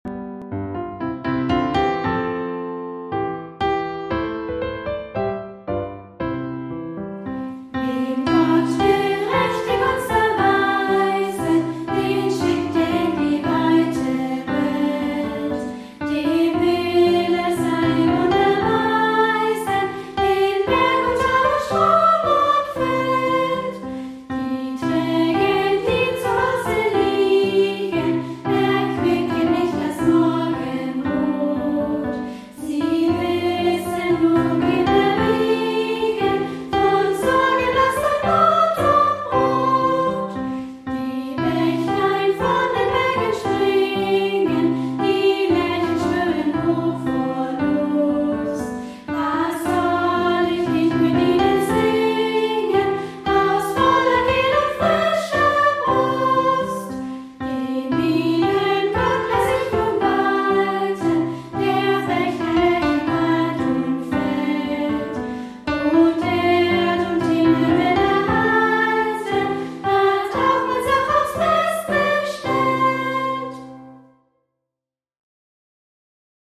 Gesungen von: Kinder- und Jugendchor Cantemus, Feuchtwangen
Klavier